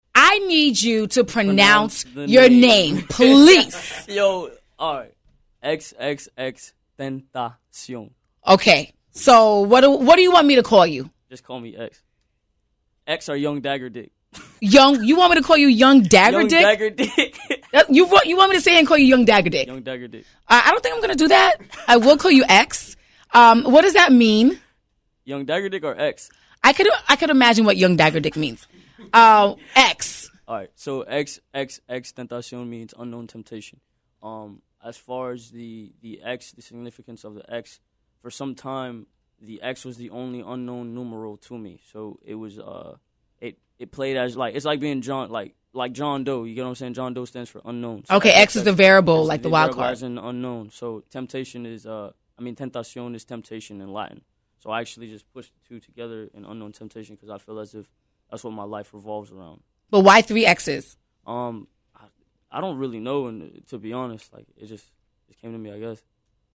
エクスエクスエクステンタシオン 　 (/ˌɛksˌɛksˌɛksˌtɛntʌsˈjɒ̃/)
本人の自己紹介からの発音（インタビュアーも読み方を教えてと要求しています）